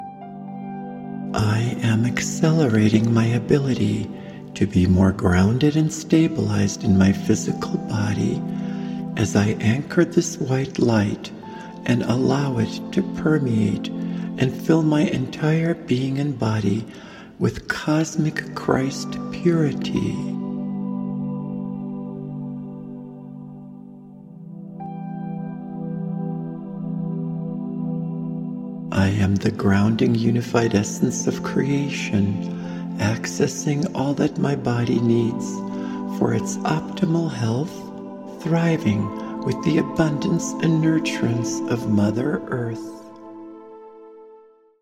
AN ADVANCED GUIDED MEDITATION